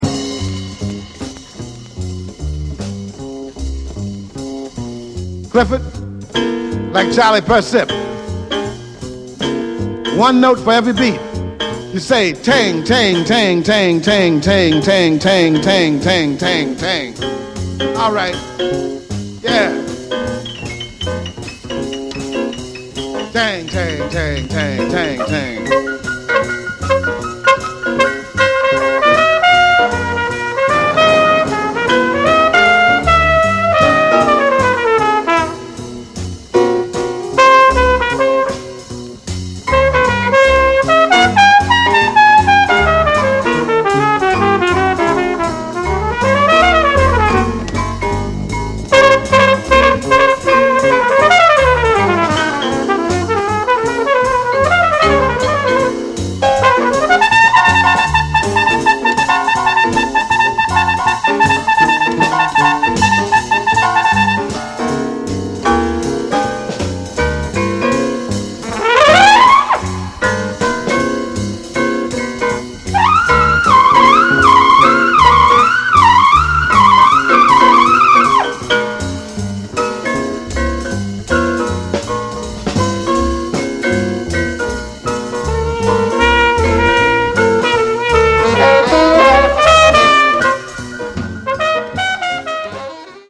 All star line-up recorded in Milan w